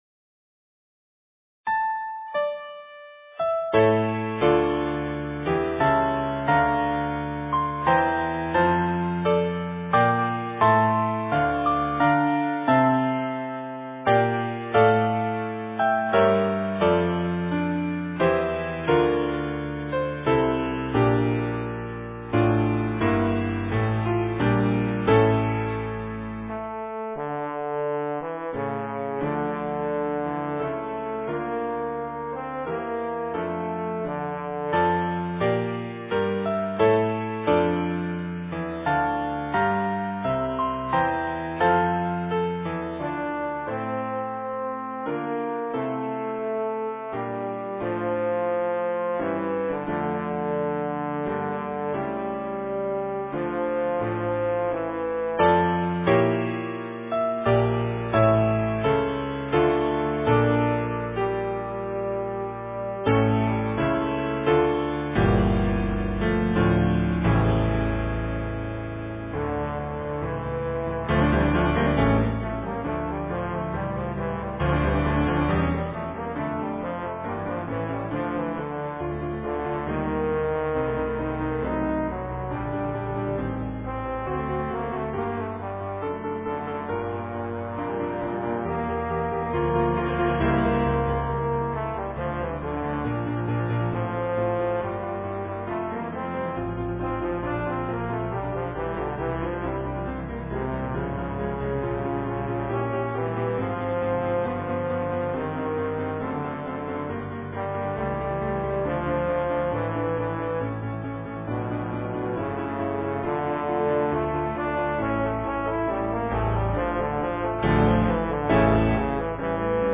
Alto Saxophone and Piano